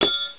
1 channel
dropmetal.wav